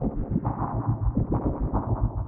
Index of /musicradar/rhythmic-inspiration-samples/105bpm